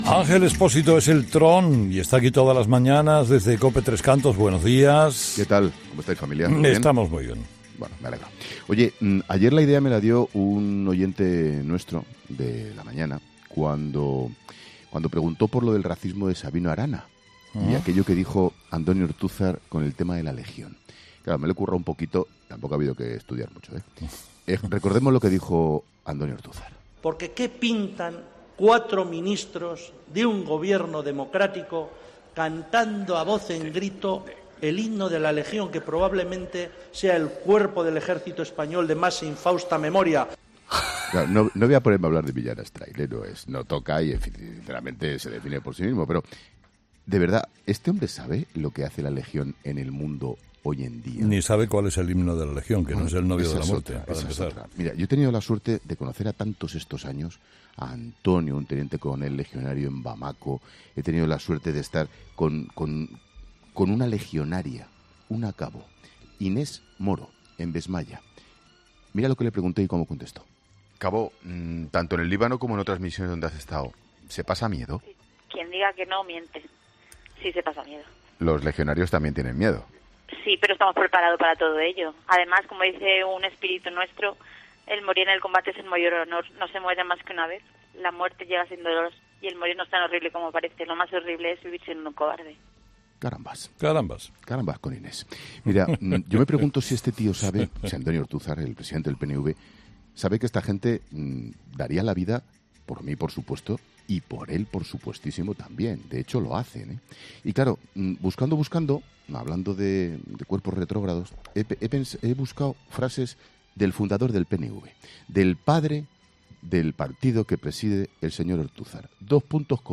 Escucha ahora el 'Paseíllo del Tron' de Ángel Expósito, emitido el 3 de marzo de 2018, en ‘Herrera en COPE’